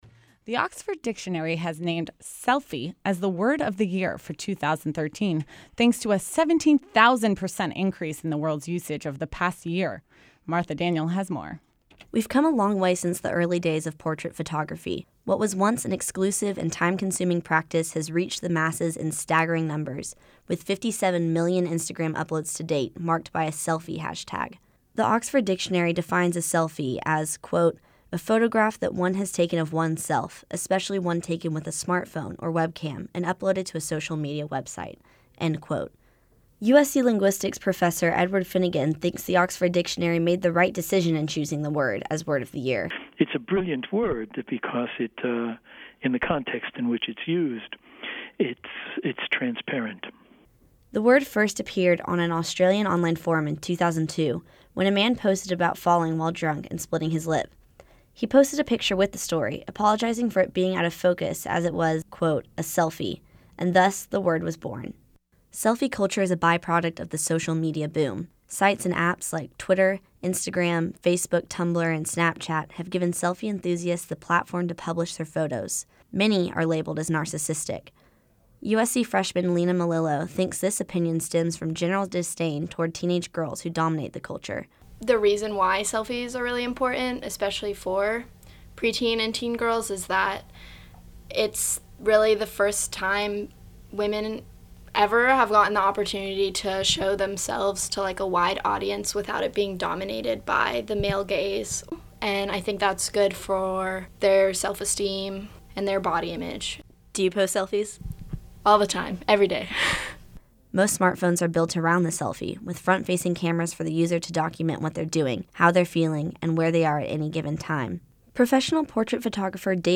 In this story, we hear from a USC linguistics professor, a selfie enthusiast, and a professional portrait photographer.